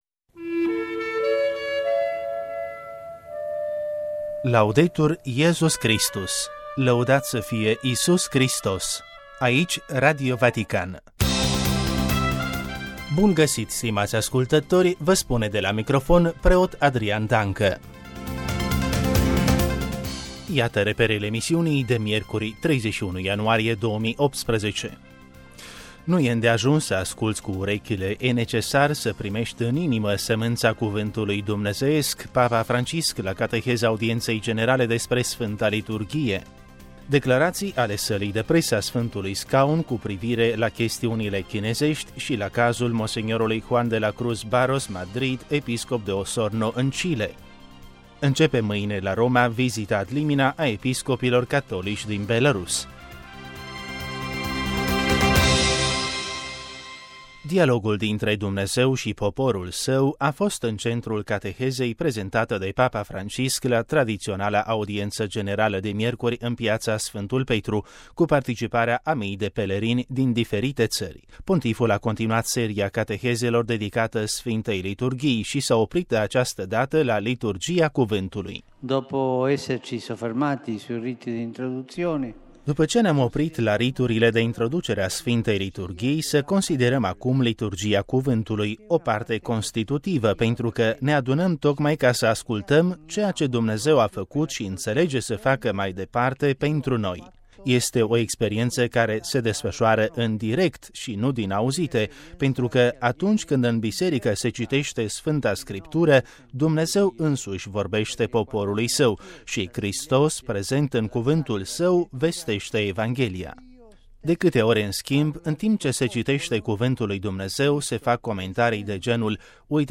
RV 31 ian 2018. Știrile zilei la Radio Vatican, difuzate în emisiunea radiofonică sau publicate pe pagina web: